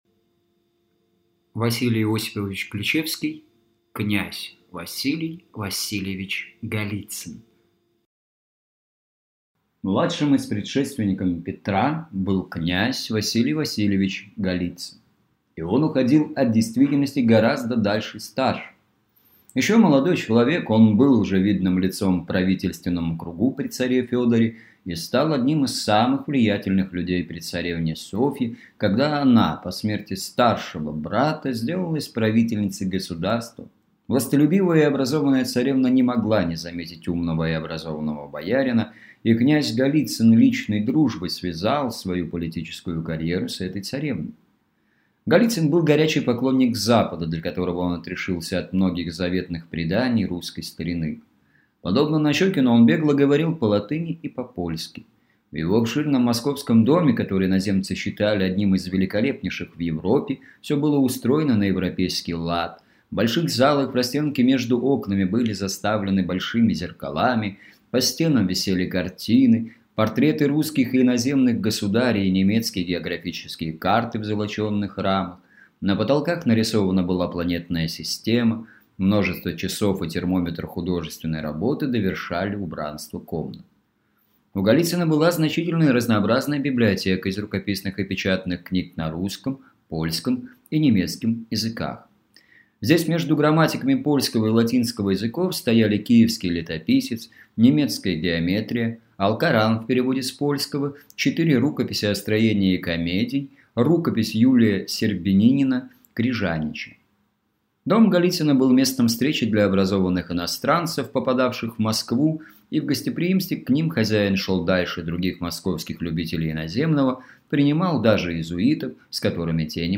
Аудиокнига Князь Василий Васильевич Голицын | Библиотека аудиокниг